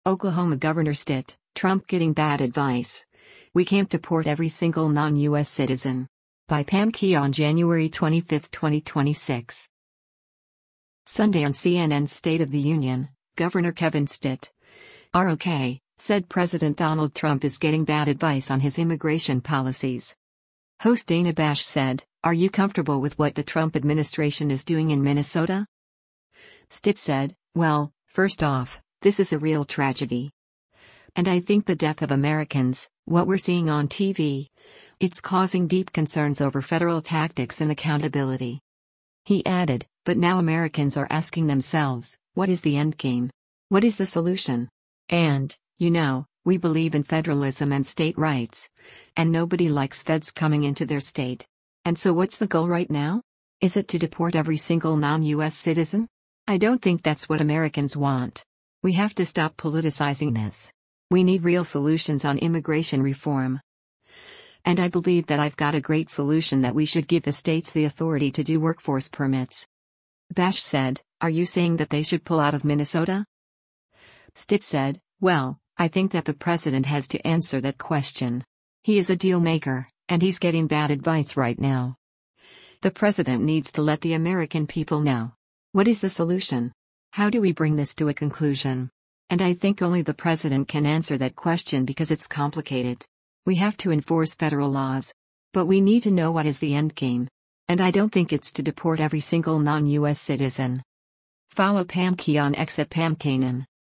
Sunday on CNN’s “State of the Union,” Gov. Kevin Stitt (R-OK) said President Donald Trump is getting “bad advice” on his immigration policies.